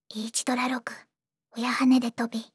voicevox-voice-corpus / ROHAN-corpus /ずんだもん_ヒソヒソ /ROHAN4600_0042.wav